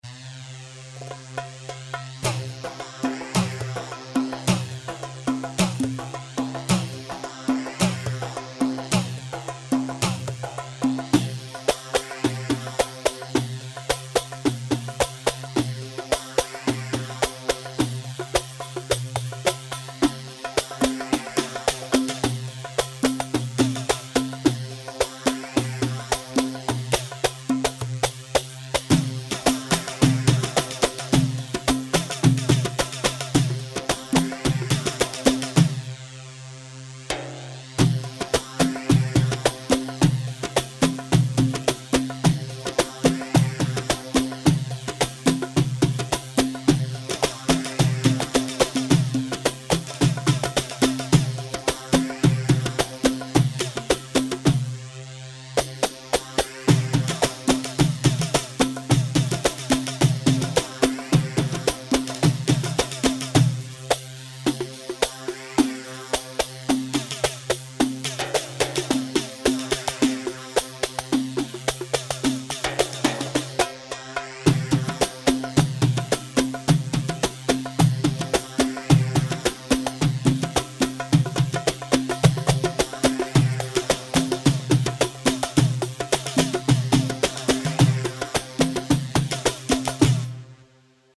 Hewa 3/4 162 هيوا